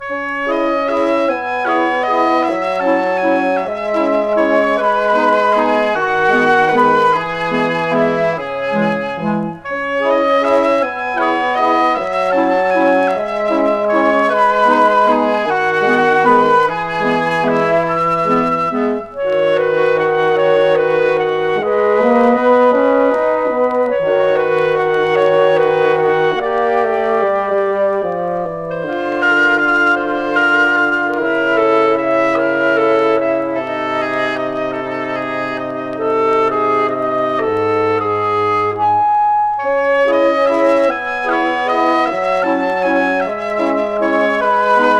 Classical, Stage & Screen　France　12inchレコード　33rpm　Stereo